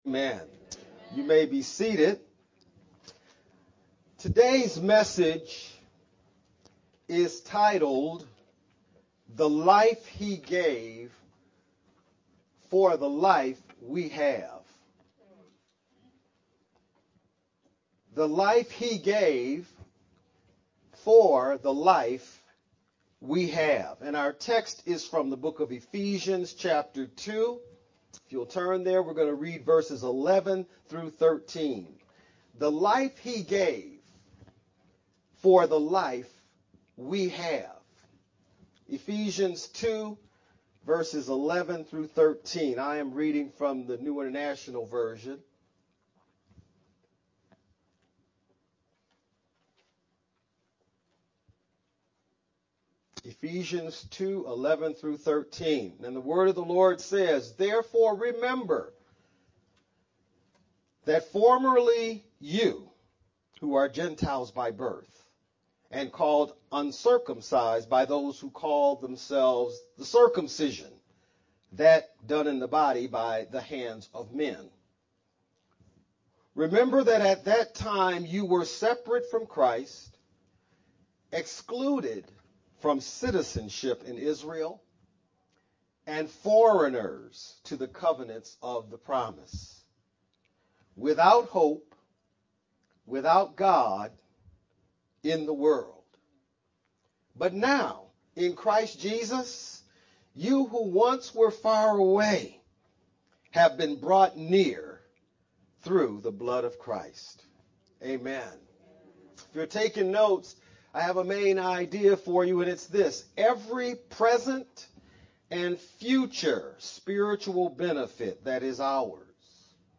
VBCC-Feb19-edited-sermon-only-MP3-CD.mp3